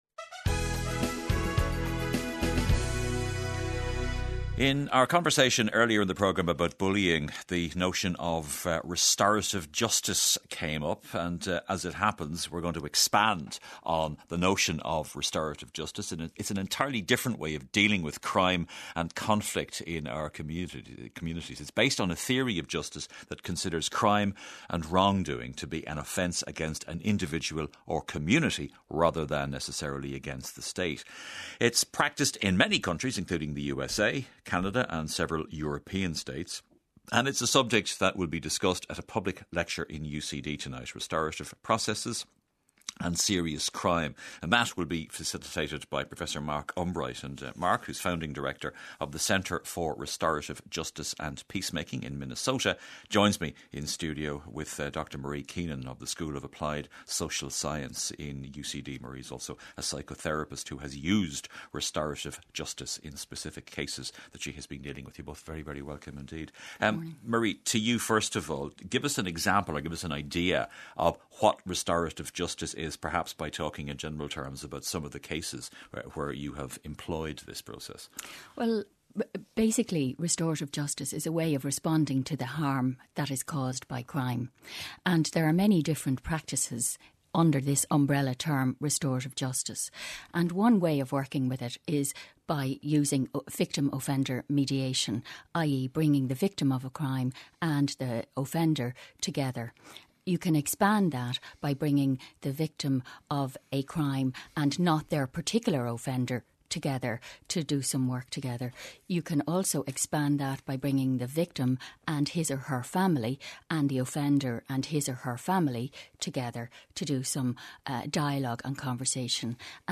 Restorative-Justice.-RTÉ-Today-with-Pat-Kenny-CastRoller2.mp3